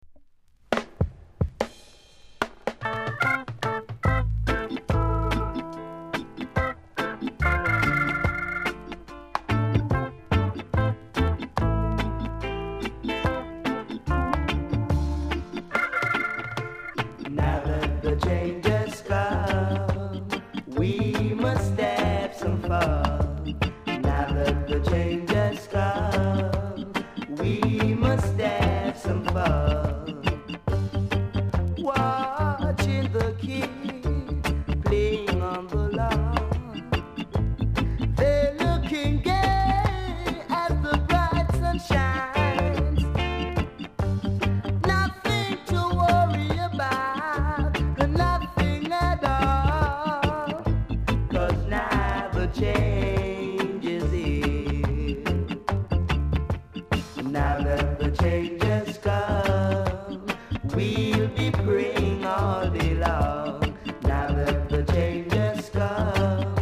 ※小さなチリノイズが少しあります。
コメント DEEP VOCAL!!